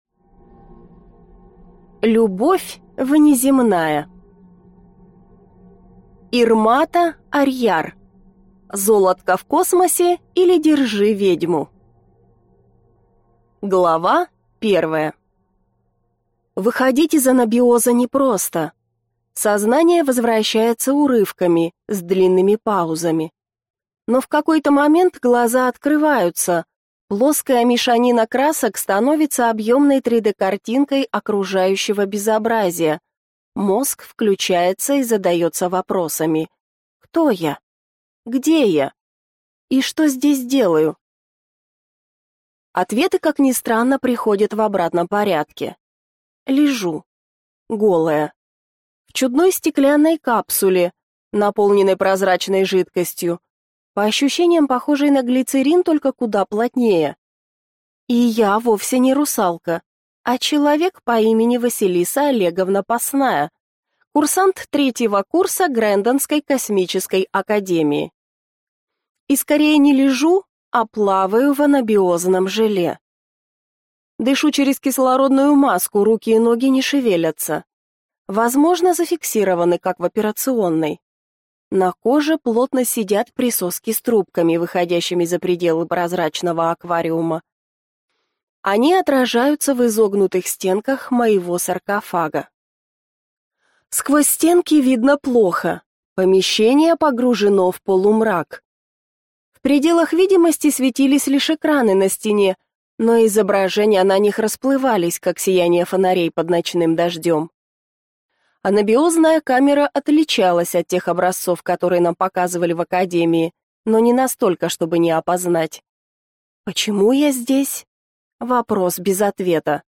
Аудиокнига Золотко в космосе, или Держи ведьму | Библиотека аудиокниг